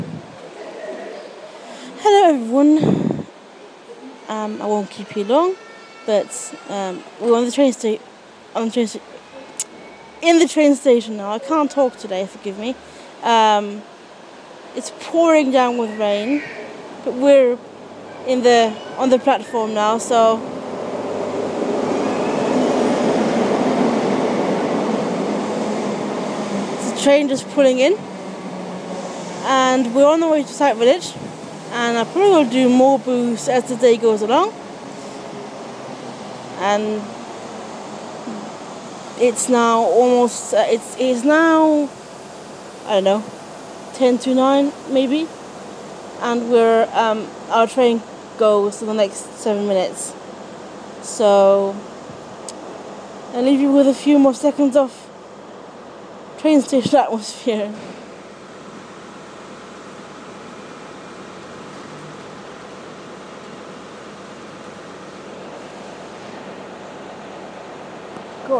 Train station